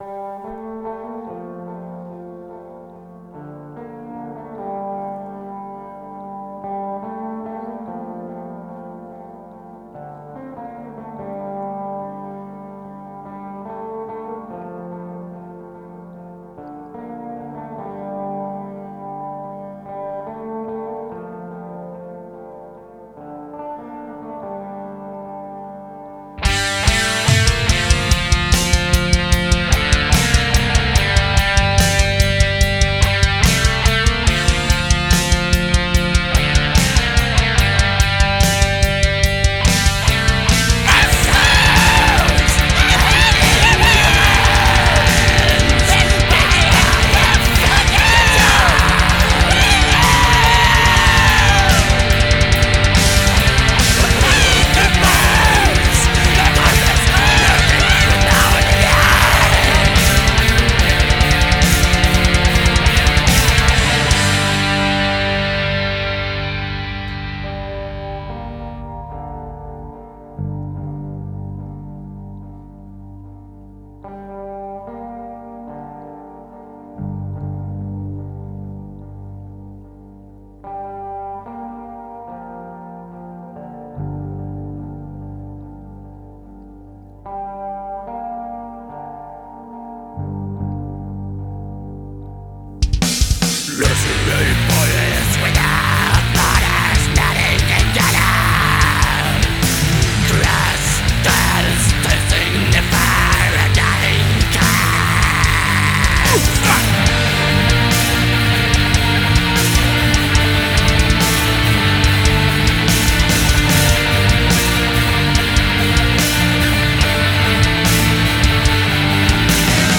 Dsbm